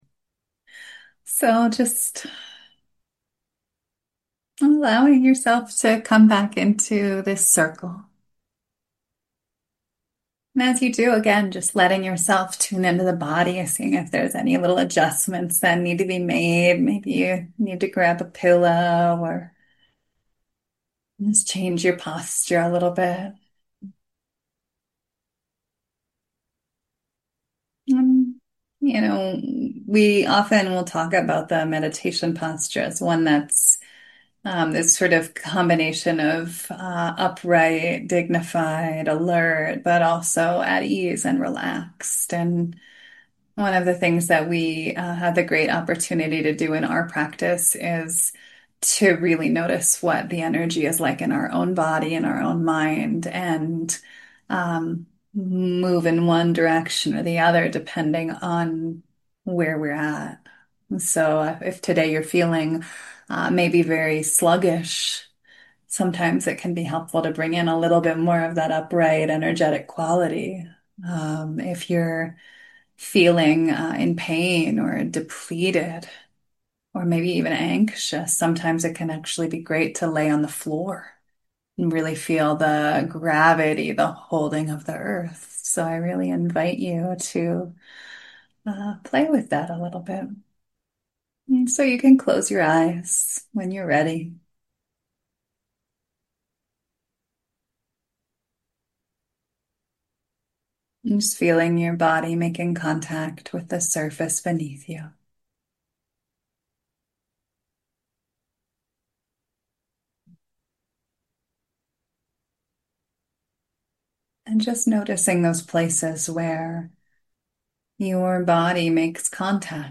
Rest, Burnout, and the Bodhisattva Vow, meditation and talk offered at Spirit Rock Meditation Center.